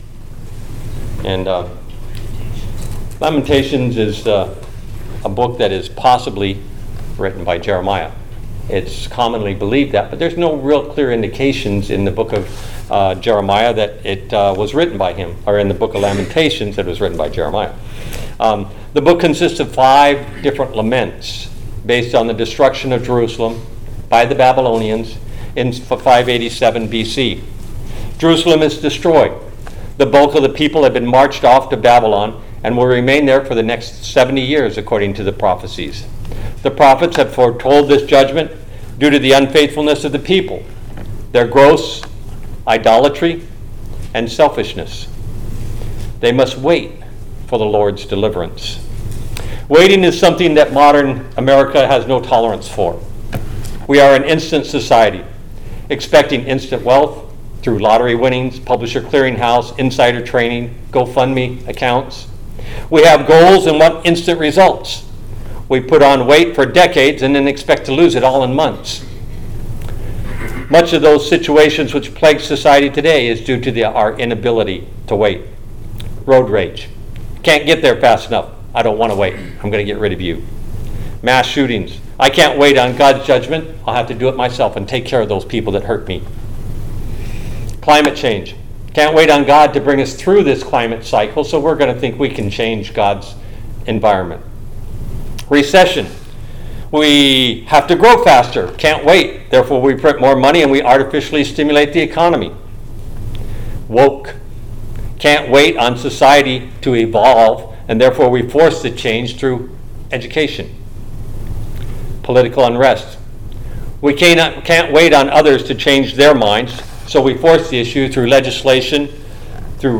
All Sermons Why Wait?